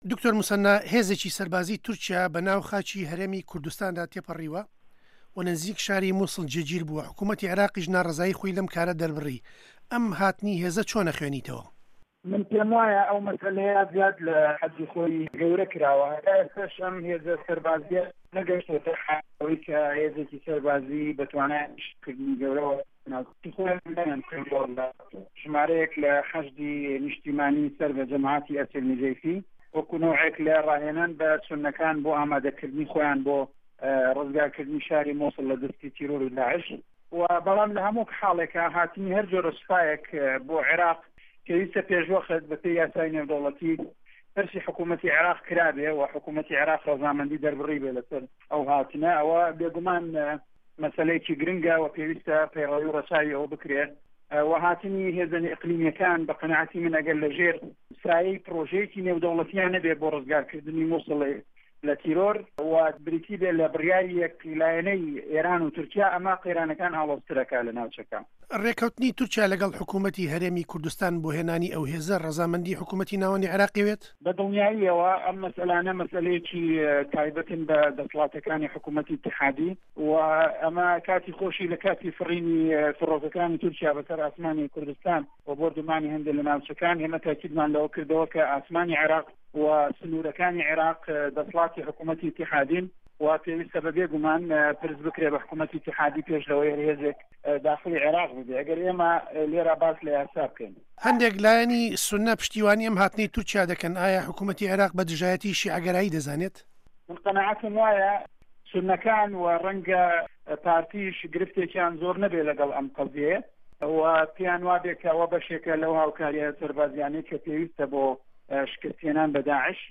له‌سه‌ر ئه‌م پرسه‌ ده‌نگی ئه‌مریکا گفتووگۆی له‌گه‌ڵ دکتۆر موسه‌نا ئه‌مین ئه‌ندام په‌رله‌مانی عێراق له‌سه‌ر لیستی یه‌گرتوه‌ی ئیسلامی سازداوه‌.
گفتووگۆ له‌گه‌ڵ دکتۆر موسه‌نا ئه‌مین